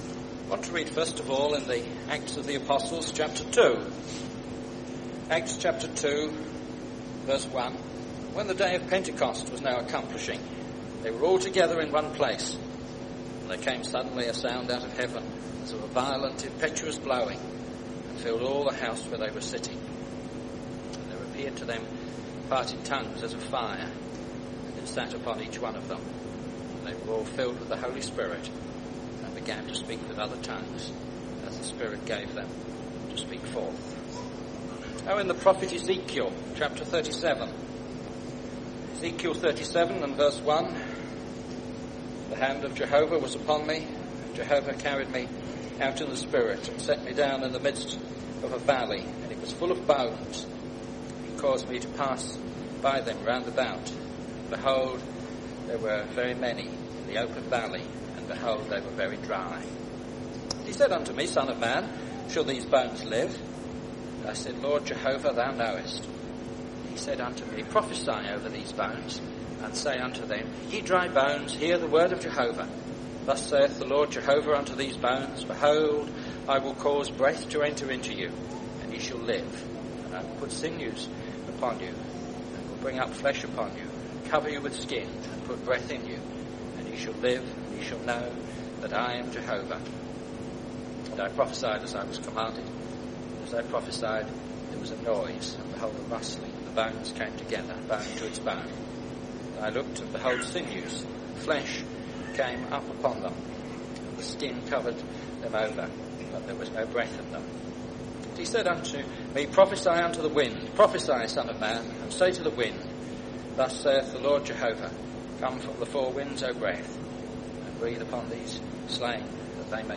The Holy Spirit is here with an objective to bring glory to the Lord Jesus Christ. In this address, you will hear what God set on when the Holy Spirit came at the beginning of Pentecost.